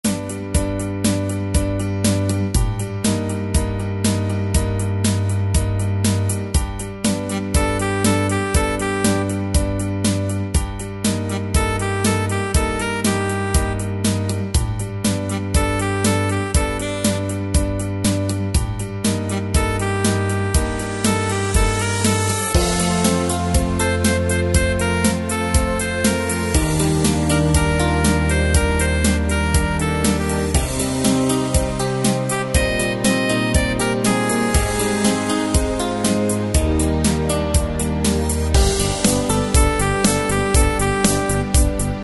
Tempo: 120 BPM.
MP3 with melody DEMO 30s (0.5 MB)zdarma